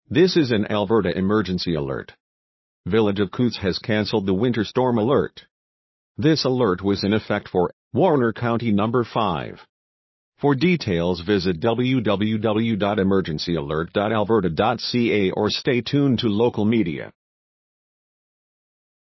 Broadcast Audio